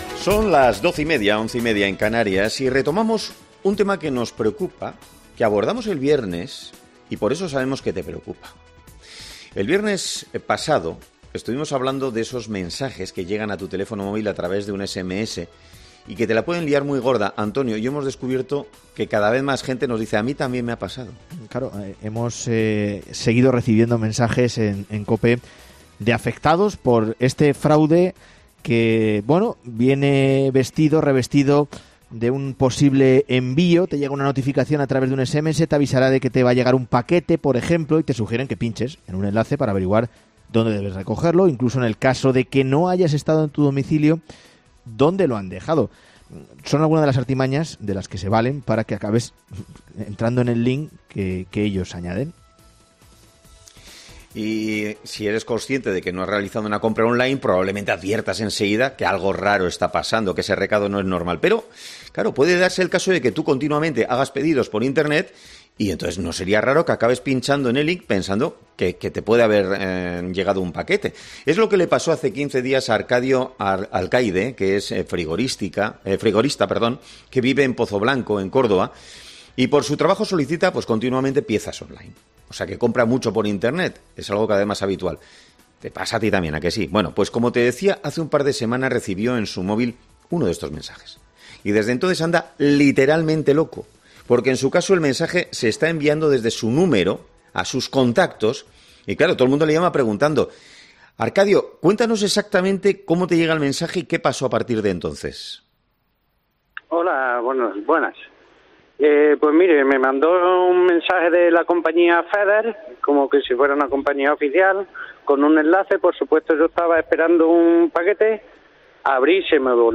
Con Carlos Herrera